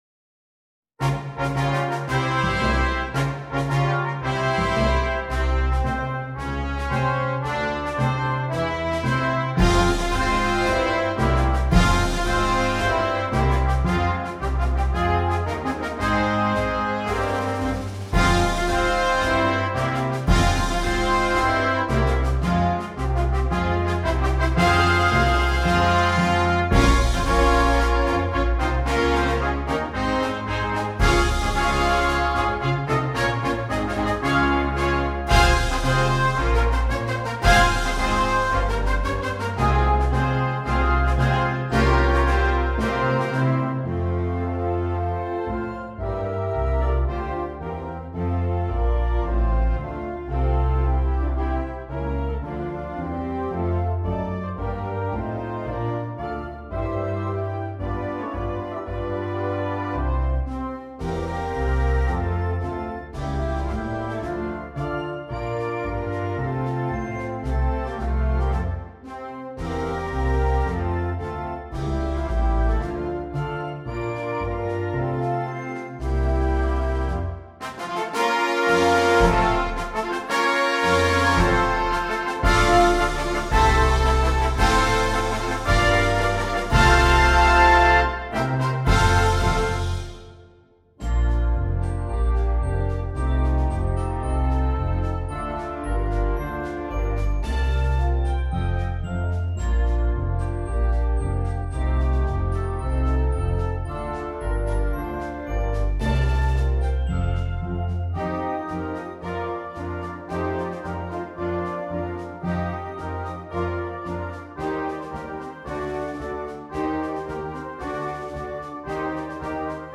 Besetzung: Concert Band